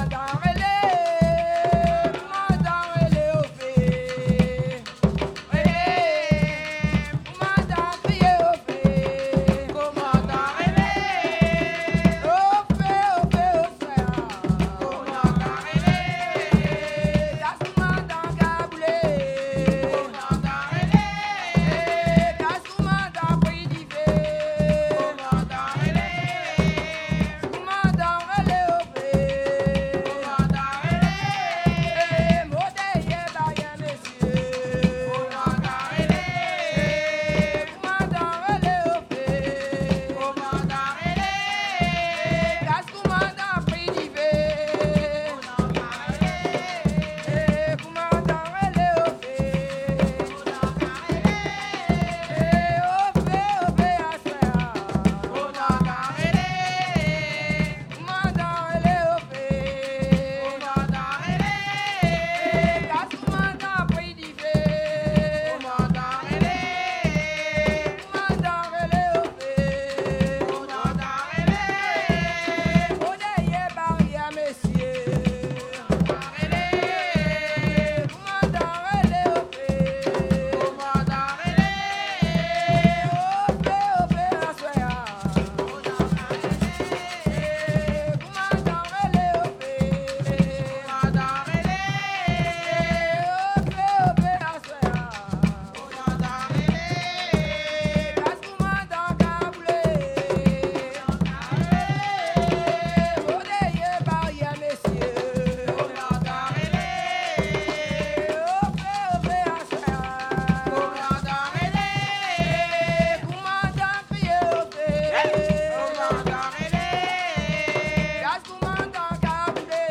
Saint-Georges-de-l'Oyapoc
danse : kasékò (créole)
Pièce musicale inédite